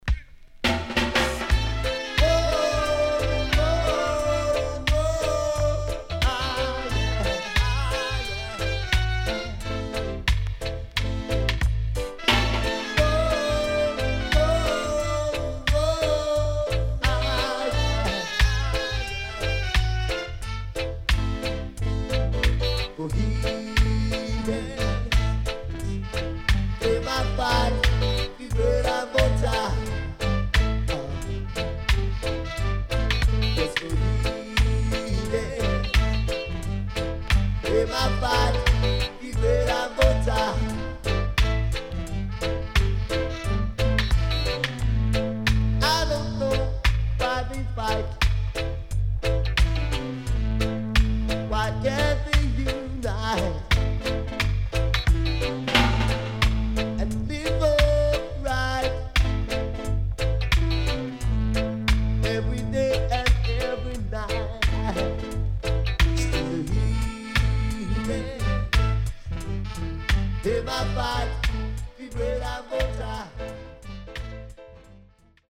HOME > LP [DANCEHALL]
SIDE A:少しノイズ入りますが良好です。